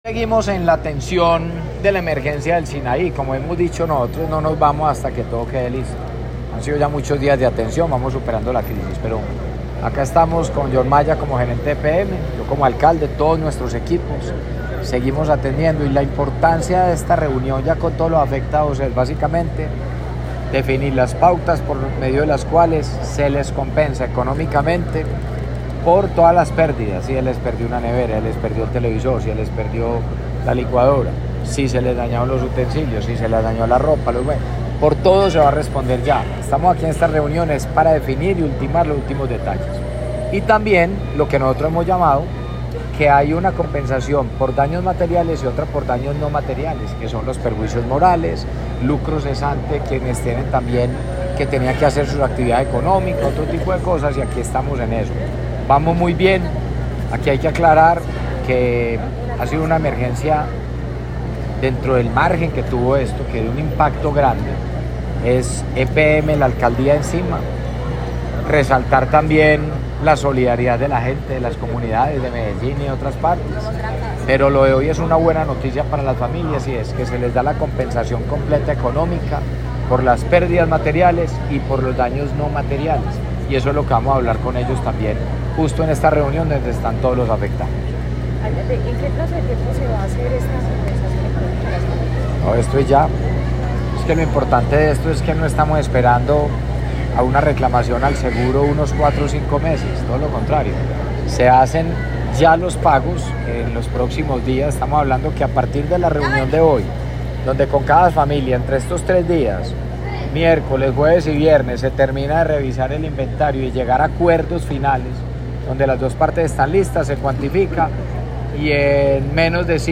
En una reunión desarrollada este miércoles en El Sinaí, el alcalde Federico Gutiérrez Zuluaga anunció que tras los acuerdos logrados con las familias afectadas por la inundación del pasado 10 de noviembre, en ese sector del nororiente de Medellín, se dará inicio a la entrega de la compensación económica definitiva.
Palabras de Federico Gutiérrez, alcalde de Medellín